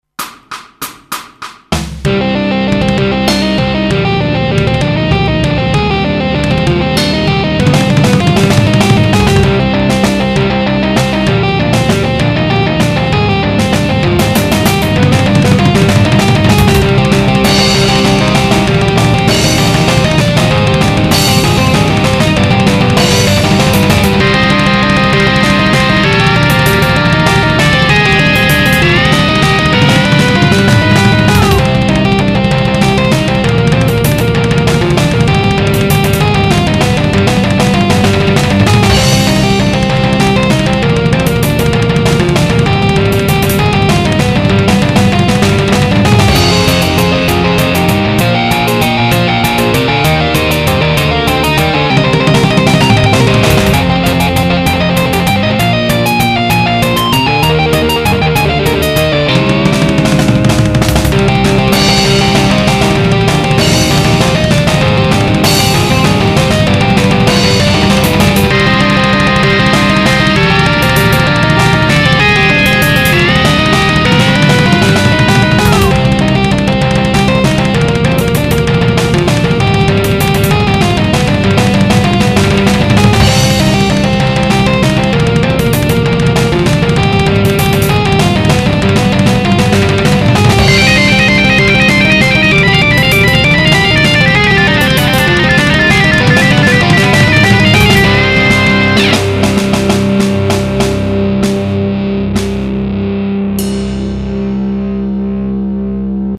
Music / Other Music
Like power metal...on my Nintendo!